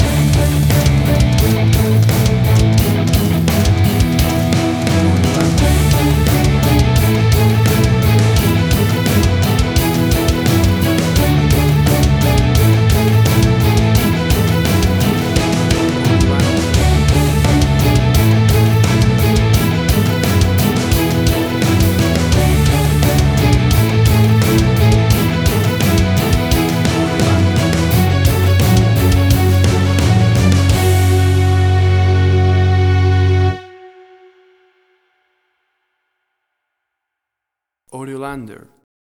Tempo (BPM): 86